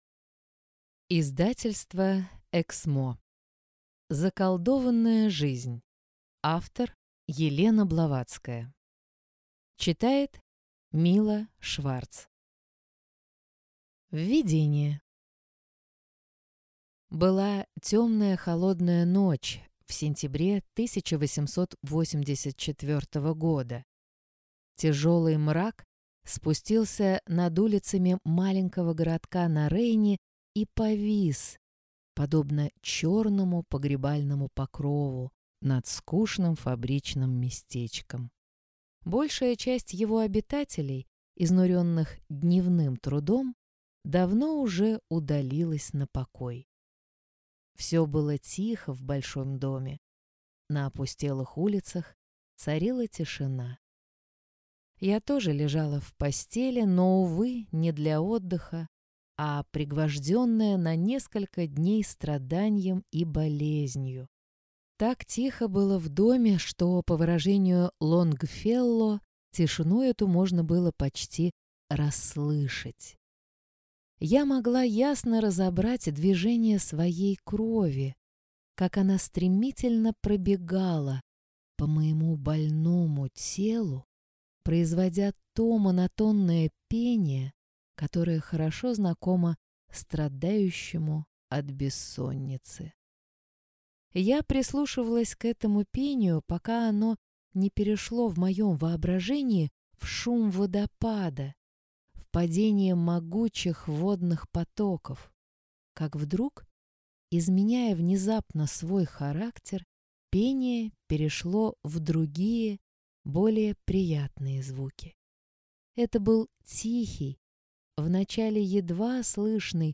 Аудиокнига Заколдованная жизнь | Библиотека аудиокниг
Прослушать и бесплатно скачать фрагмент аудиокниги